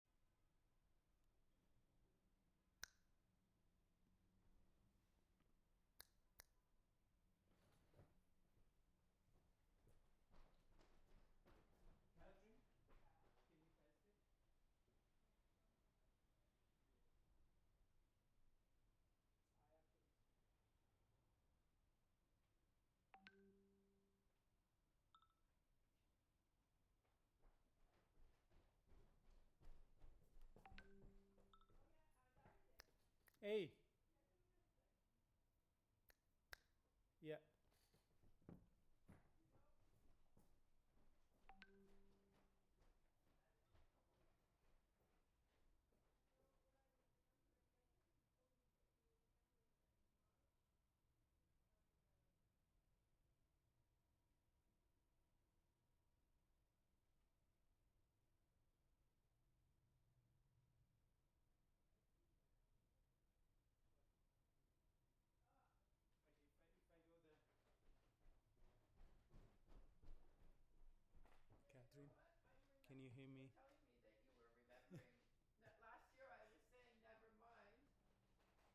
Live from Experimental Intermedia